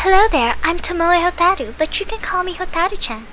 Sense we haven't started the dub yet I put up audition clips.